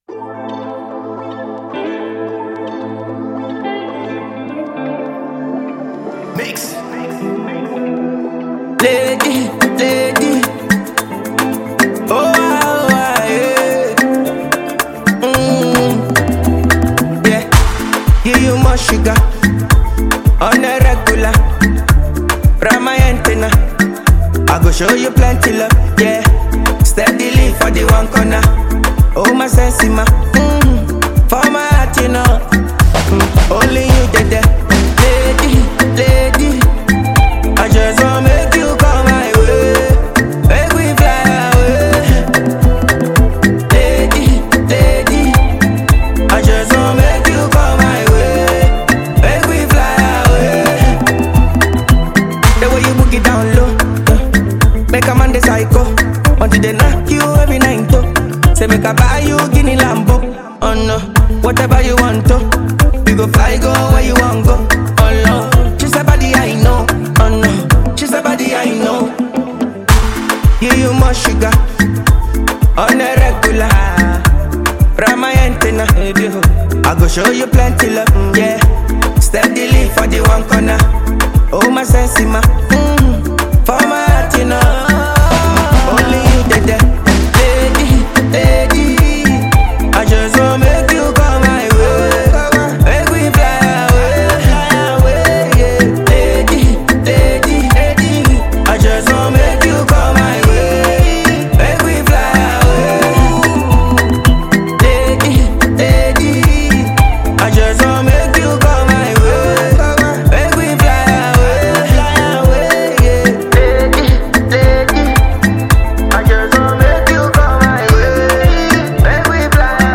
A multi-talented Ghanaian afrobeat sensation and songwriter